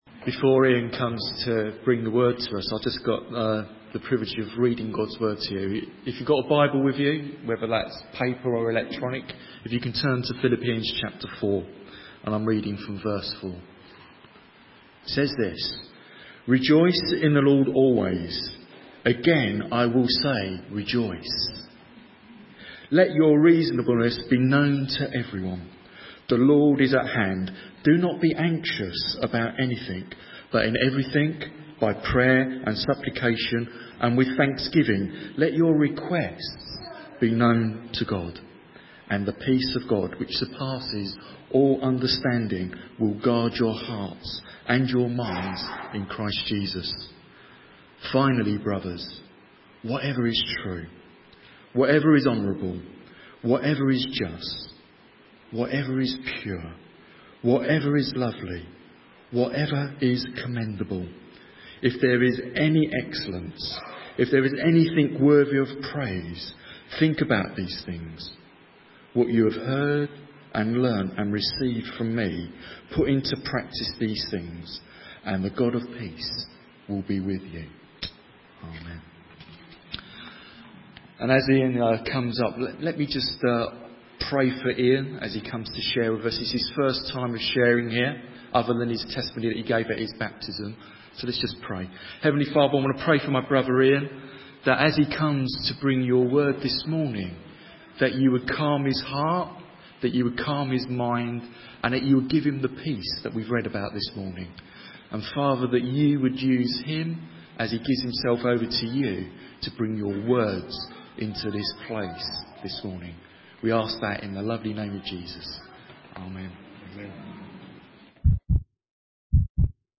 A message from the series "Missional Heart."